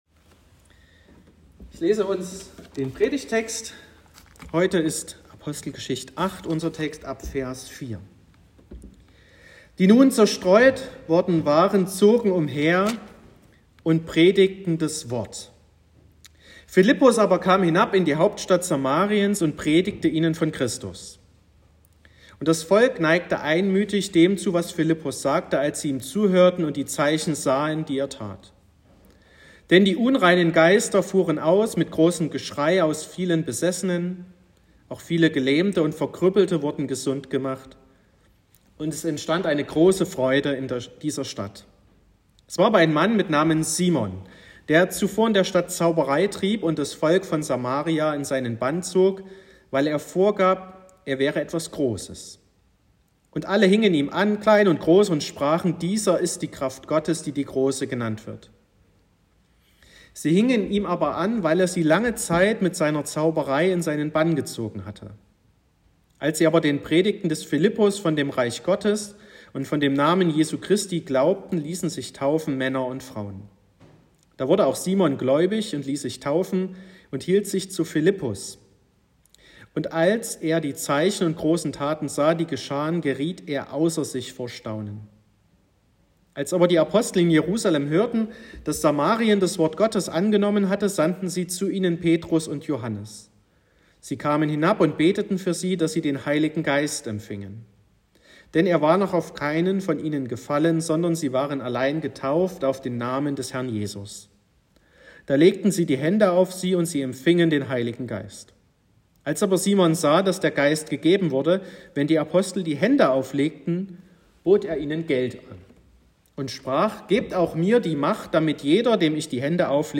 10.09.2023 – Gottesdienst zur Bibelwoche
Predigt und Aufzeichnungen
Predigt (Audio): 2023-09-10_Mit_dem_Heiligen_Geist__Bibelwoche_2023__Tehma_2_.m4a (11,0 MB)